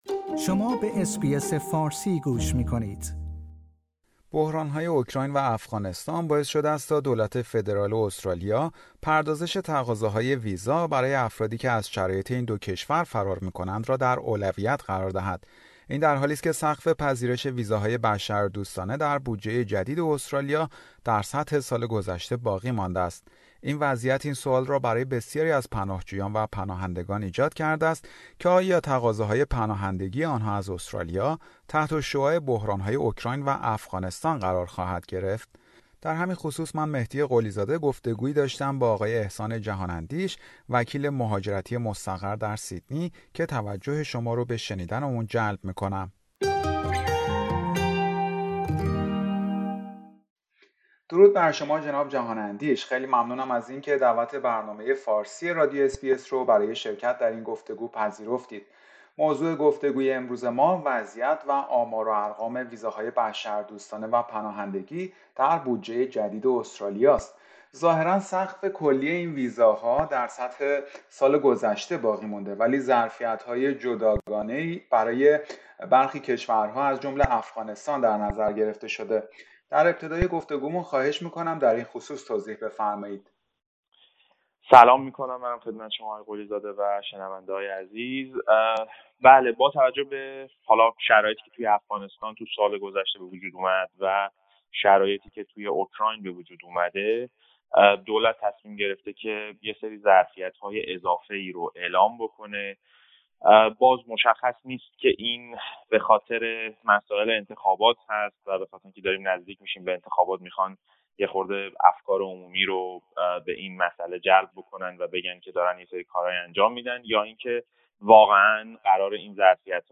در همین خصوص، برنامه فارسی رادیو اس بی اس گفتگویی داشته